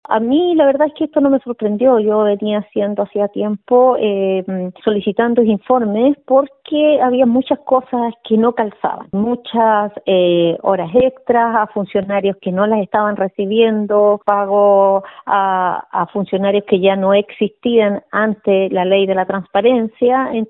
Ante ello, la concejal independiente cercana a Amarillos, Adriana Quintana, y que a la vez oficia como presidenta de la Comisión de Educación del Concejo, dijo que esto no le sorprende, indicando que habría otras irregularidades al interior del DAEM.